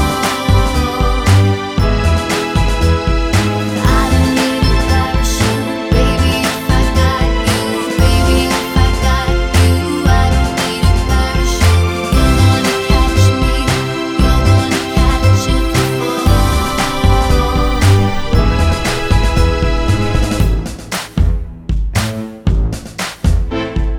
no Backing Vocals Pop (2010s) 3:48 Buy £1.50